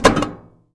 metal_tin_impacts_movement_rattle_05.wav